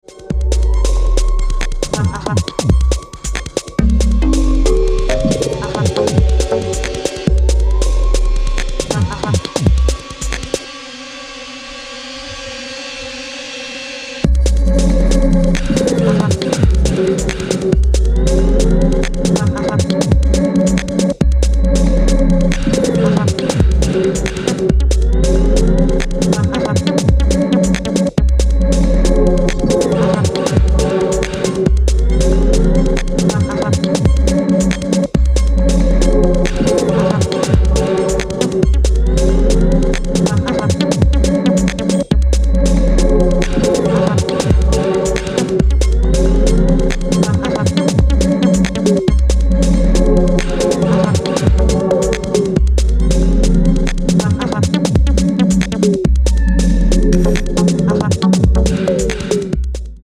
More minimal deepstep runnins